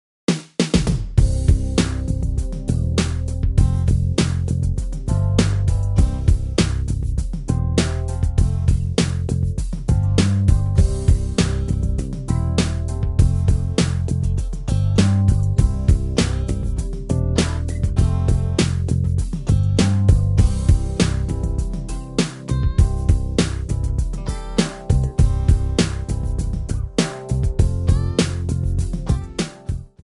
Backing track files: 1990s (2737)
Buy With Backing Vocals.